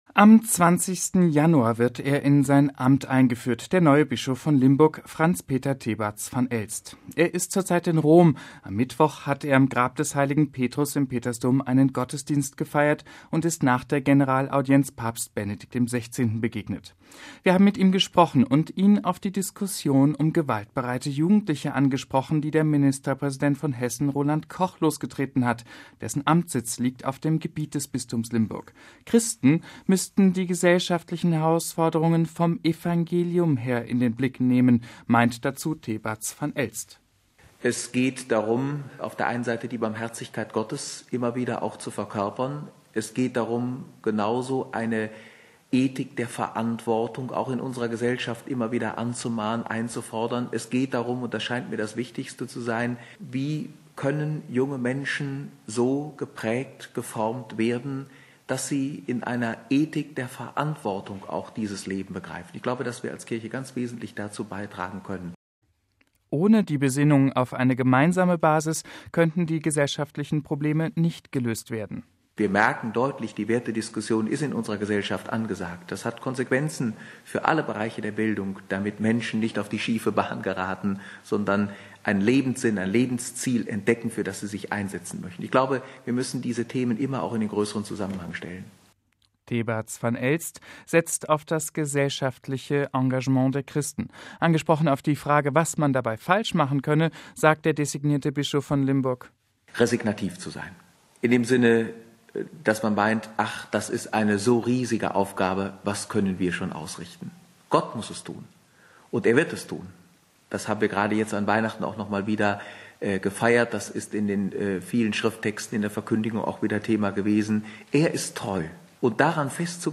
Wir haben mit ihm gesprochen und ihn auf die Diskussion um gewaltbereite Jugendliche angesprochen, die der Ministerpräsident von Hessen Roland Koch losgetreten hat.